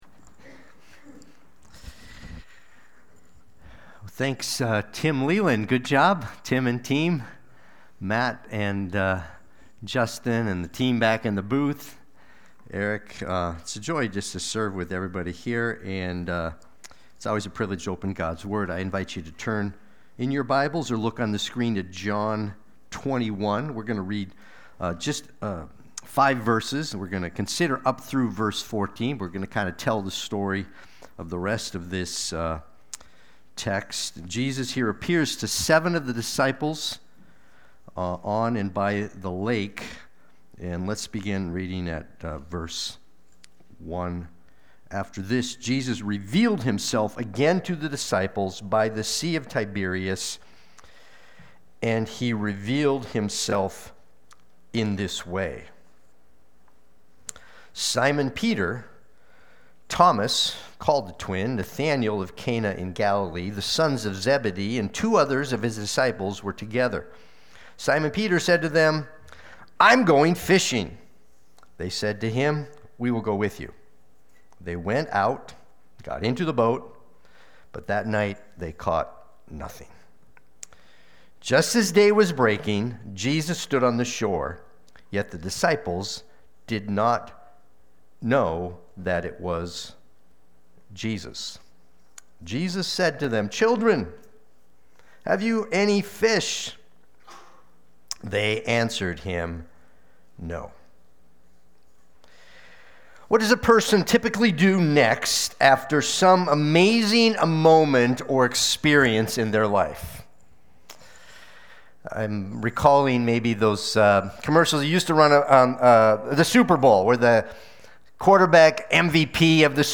Watch the replay or listen to the sermon.
Sunday-Worship-main-4625.mp3